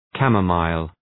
Προφορά
{‘kæmə,maıl, ‘kæmə,mi:l}